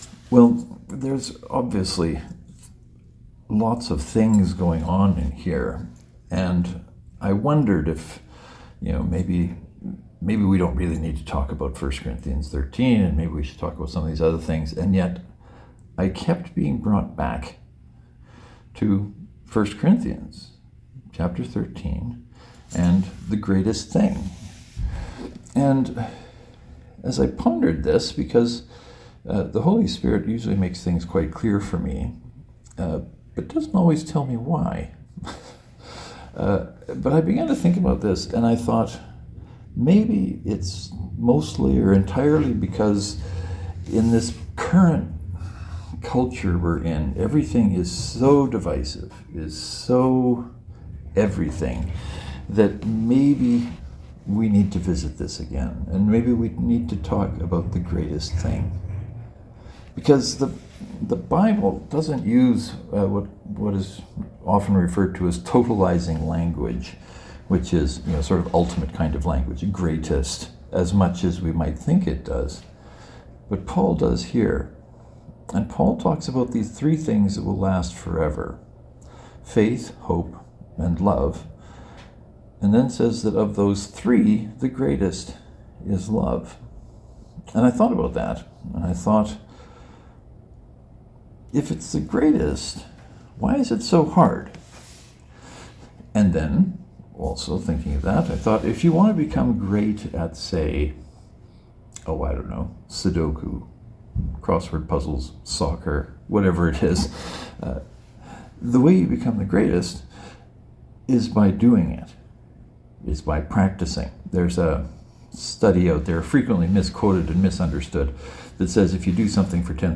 The sermon may sound a little different as it was delivered from a hotel as the weather rendered travel too untrustworthy for me to risk travelling to Grenfell.